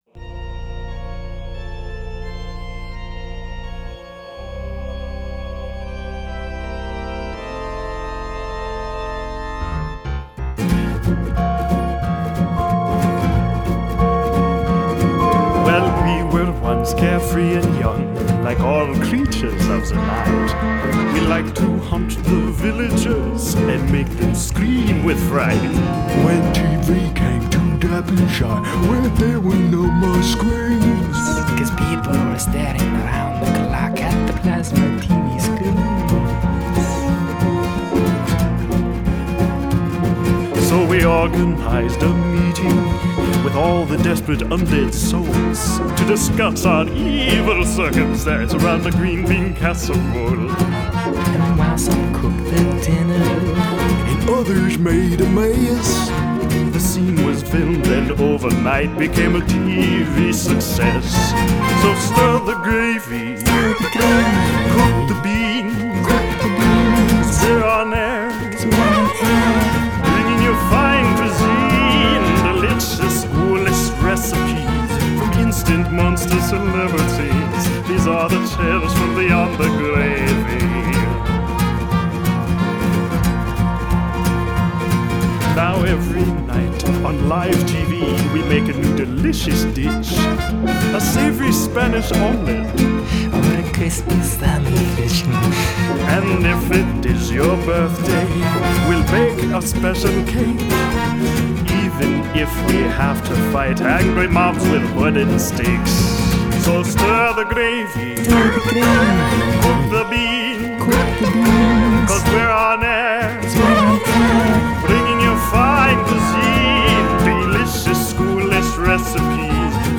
style: horror/jazz